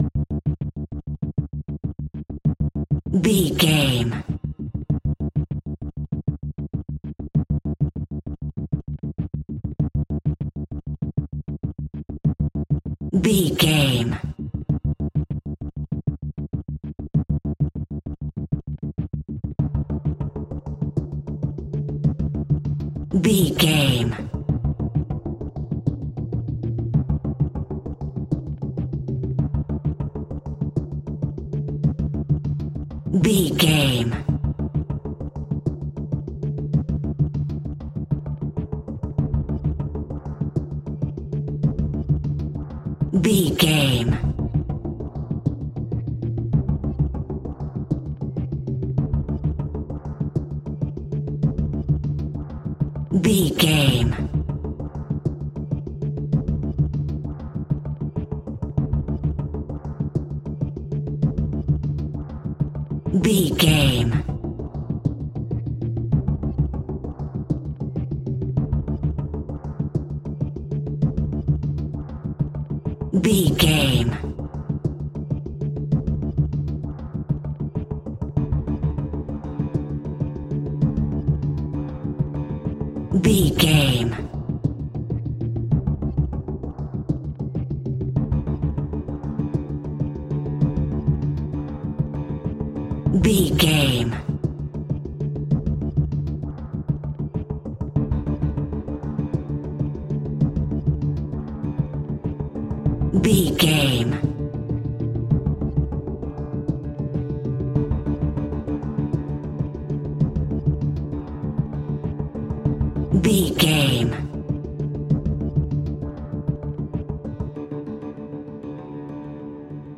Thriller
Aeolian/Minor
D
scary
tension
ominous
dark
suspense
haunting
eerie
synthesizer
percussion
mysterious